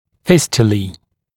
[‘fɪstjəliː] [-ʧə-][‘фистйэли:], [-чэ-]фистулы